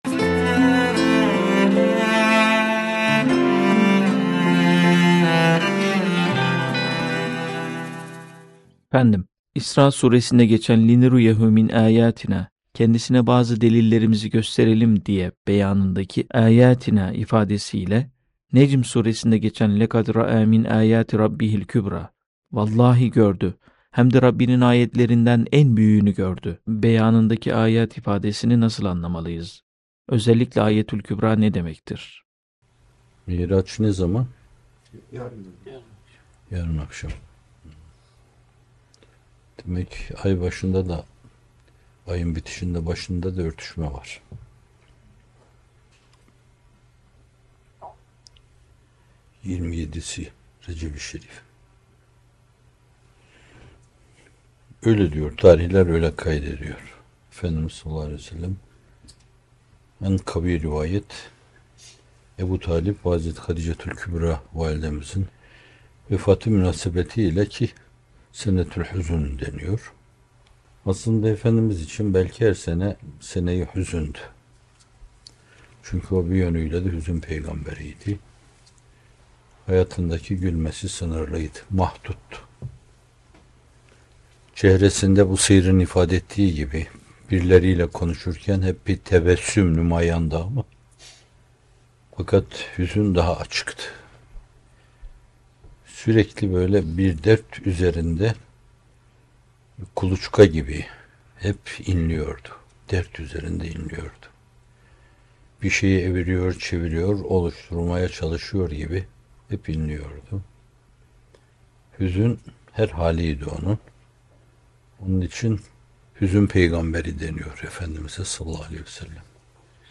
Âyetü’l-Kübrâ Nedir? İsrâ ve Necm Sûreleri Işığında Mi’raç - Fethullah Gülen Hocaefendi'nin Sohbetleri